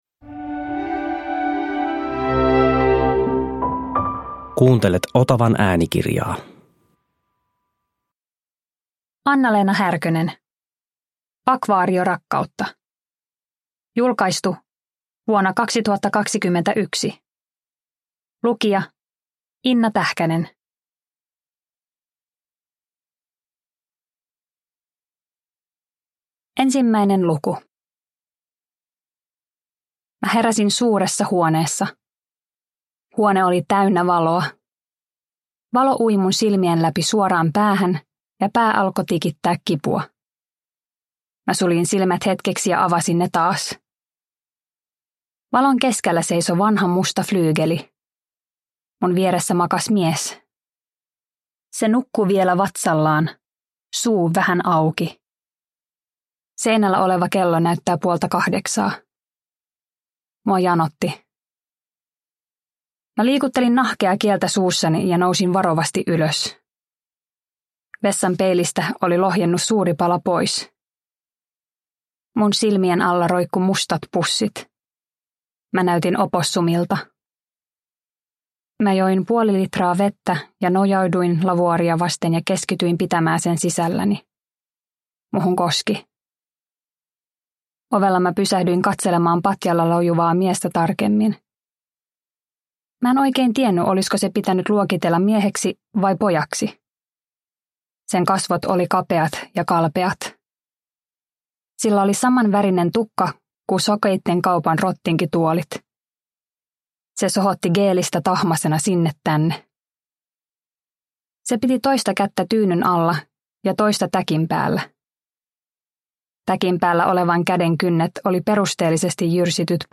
Akvaariorakkautta – Ljudbok – Laddas ner